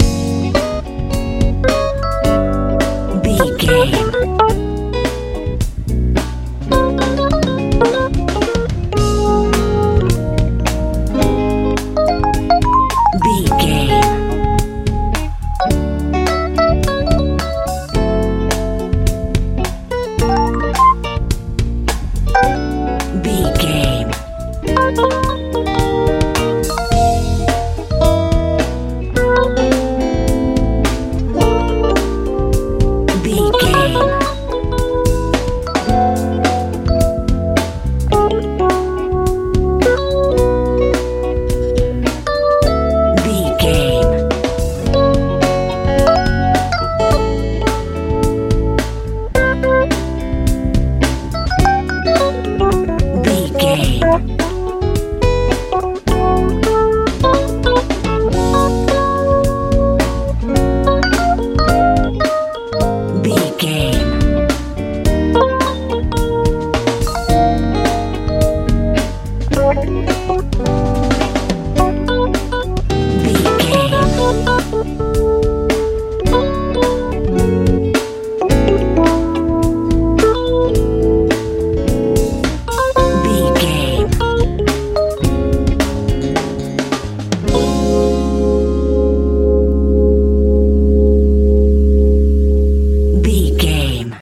funk feel
Ionian/Major
funky
electric piano
electric guitar
bass guitar
drums
80s
90s